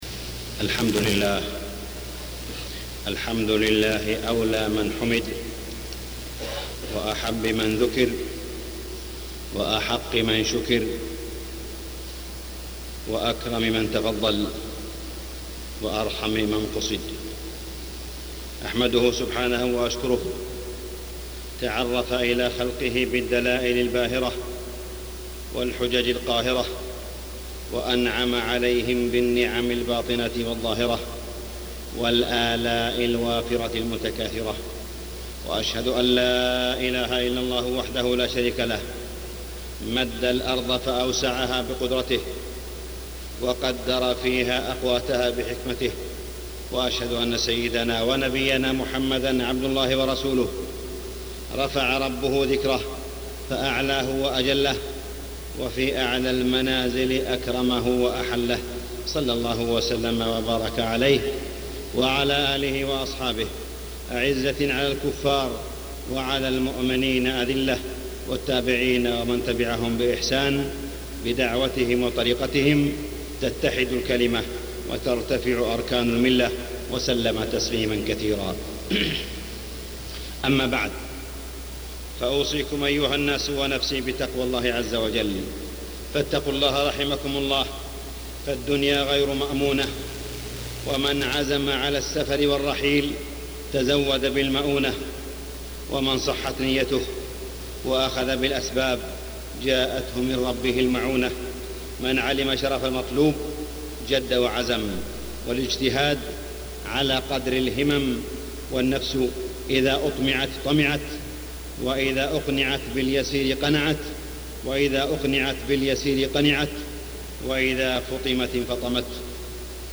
تاريخ النشر ١٠ جمادى الأولى ١٤٢٦ هـ المكان: المسجد الحرام الشيخ: معالي الشيخ أ.د. صالح بن عبدالله بن حميد معالي الشيخ أ.د. صالح بن عبدالله بن حميد أمة تنام النهار وتصحو الليل